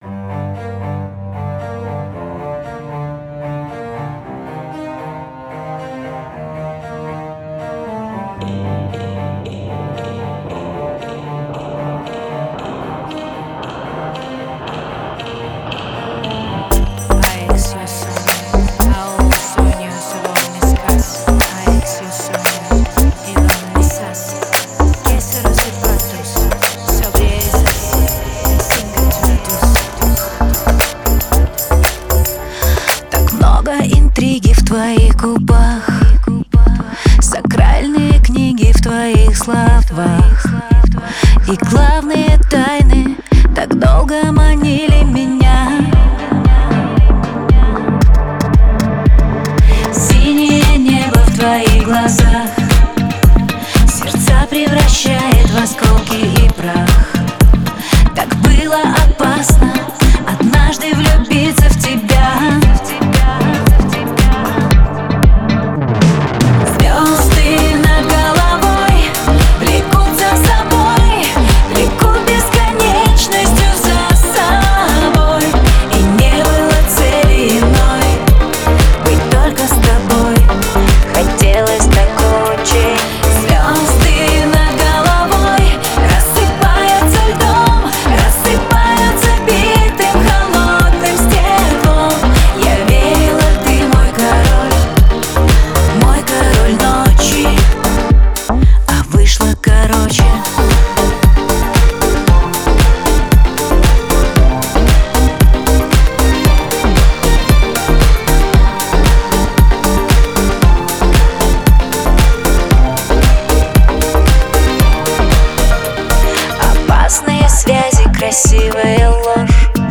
атмосферными синтезаторами